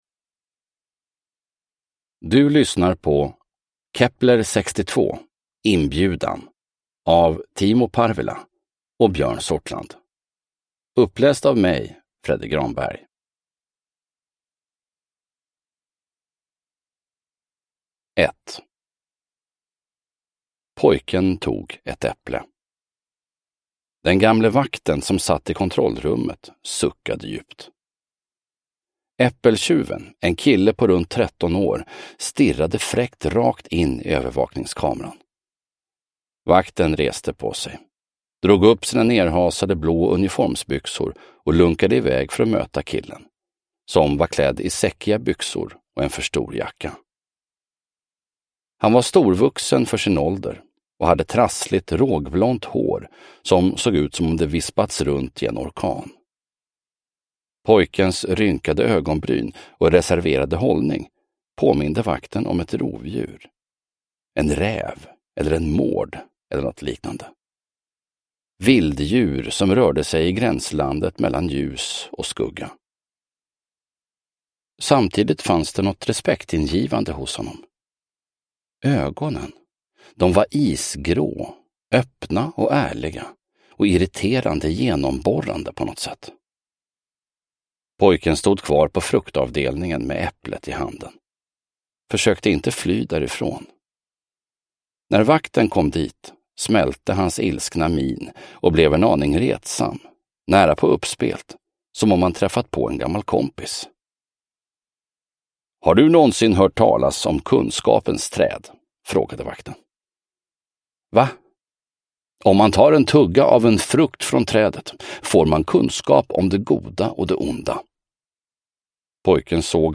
Inbjudan – Ljudbok – Laddas ner
Uppläsare